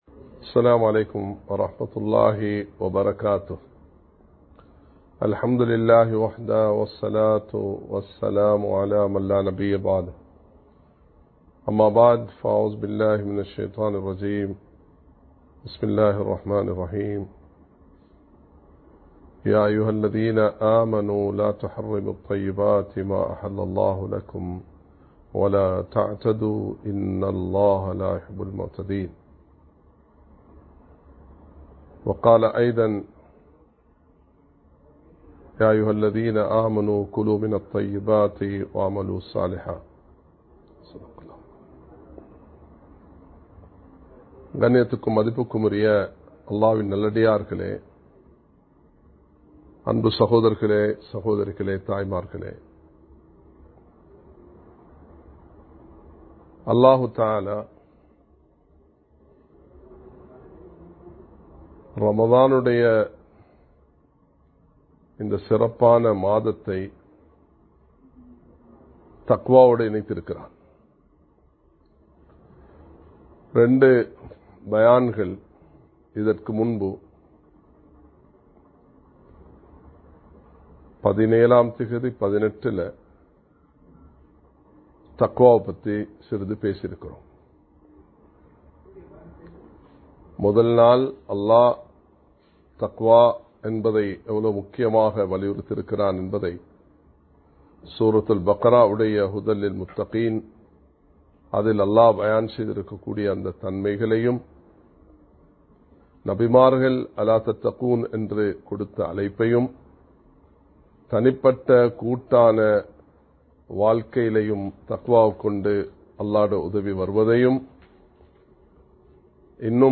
ஹராத்தில் தக்வா உருவாக முடியாது (The Fear of Allah Can not be Formed in Prohibited Way) | Audio Bayans | All Ceylon Muslim Youth Community | Addalaichenai
Live Stream